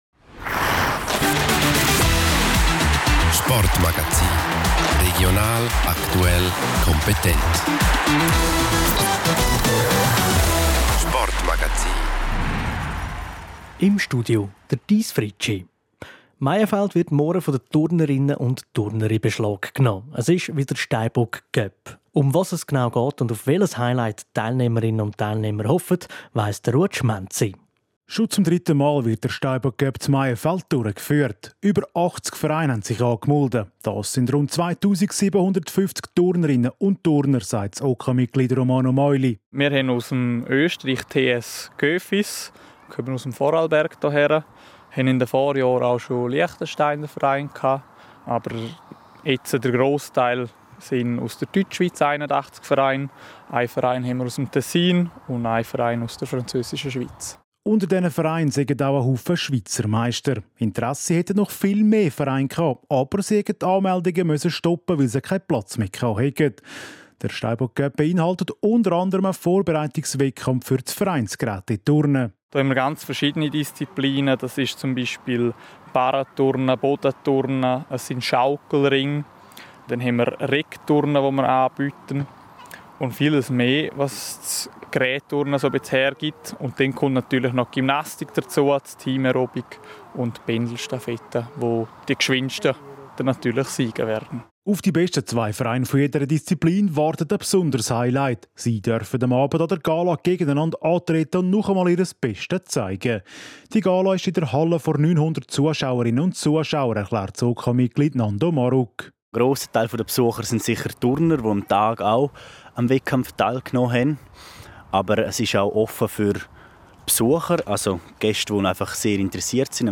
Das die Turnerinnen und die Zuschauer erwartet, erklären zwei OK-Mitglieder.